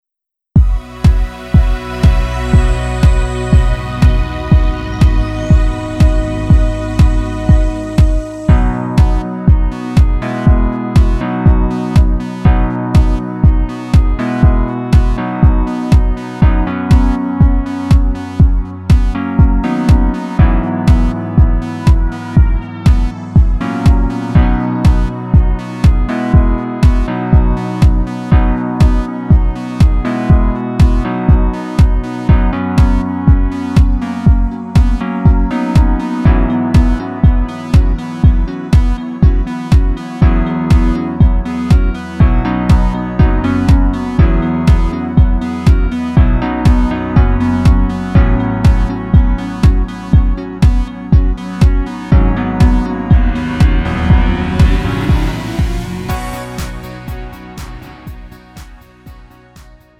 음정 -1키 3:57
장르 구분 Lite MR